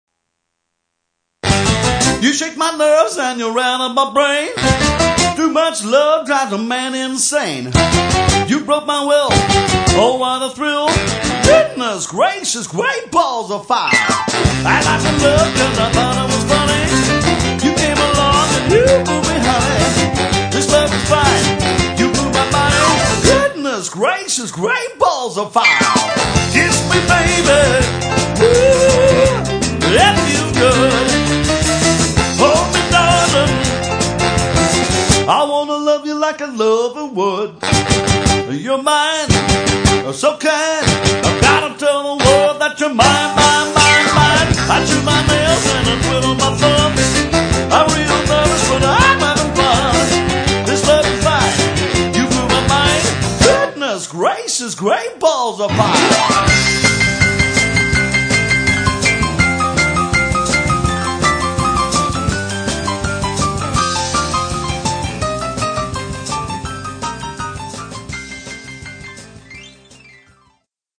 Oldies / Rock